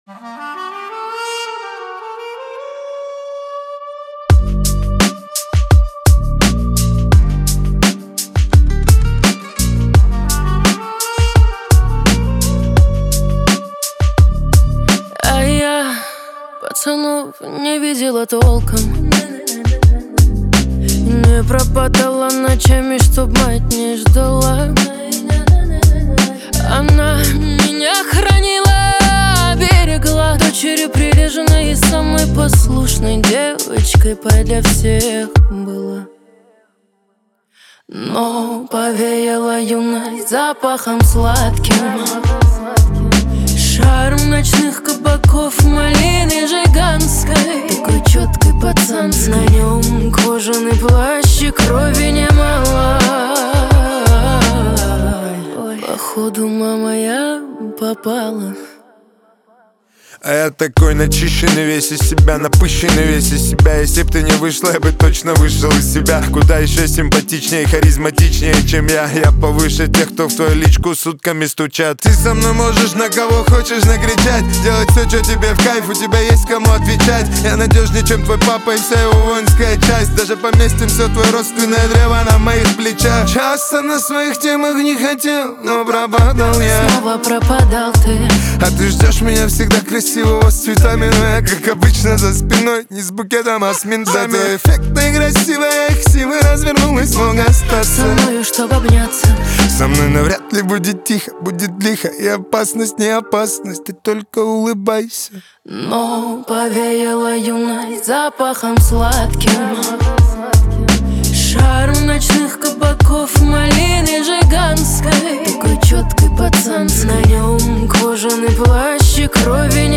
Рэп, Поп музыка